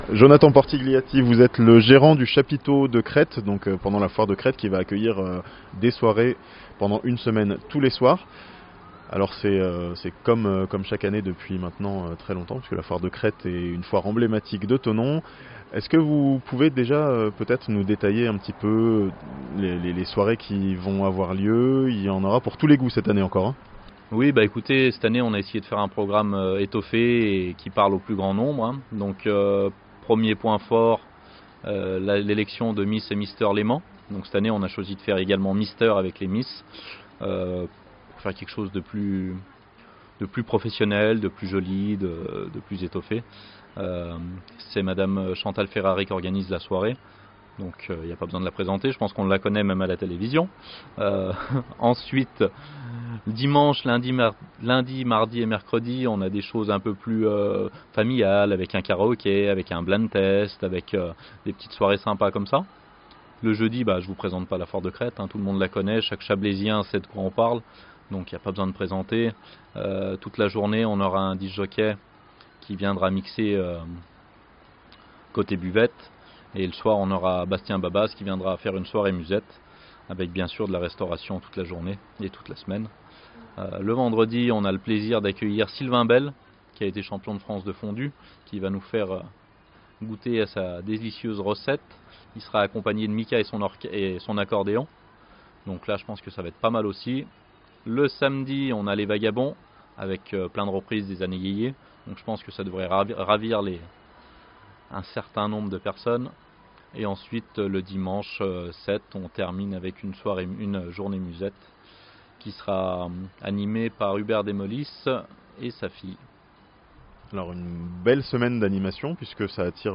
Une semaine d'animations pour la foire de Crête, à Thonon (interview)